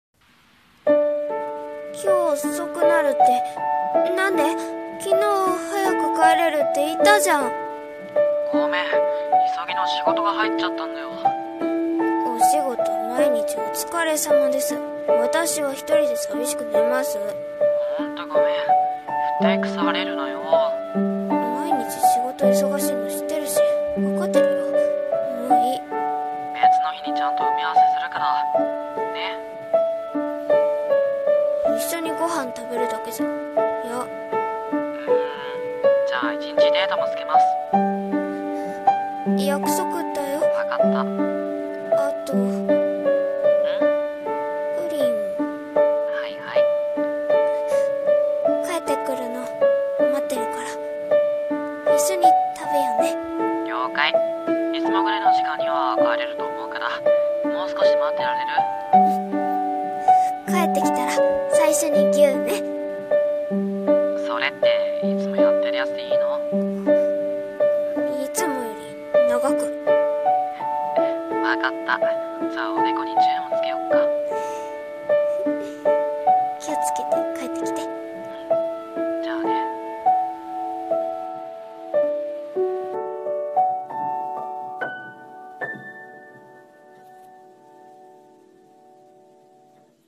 電話【声劇(2人用)